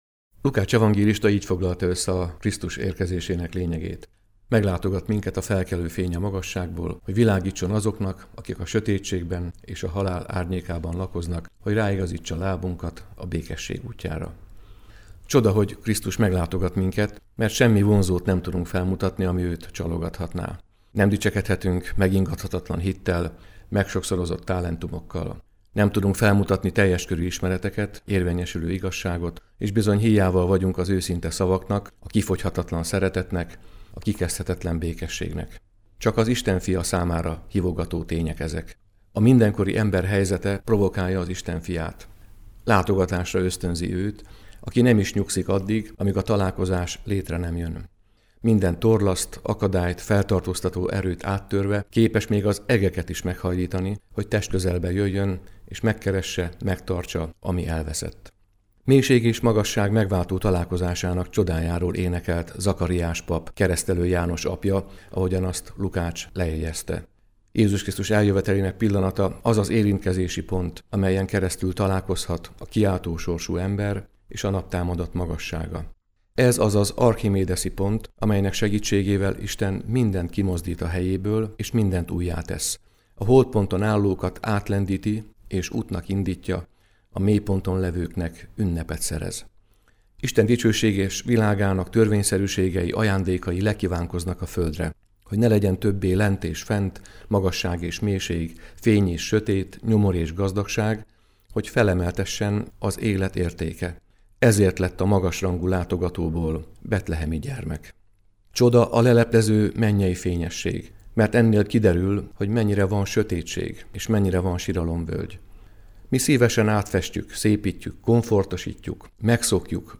puspok-adventiuzenet-ttrehonlap.mp3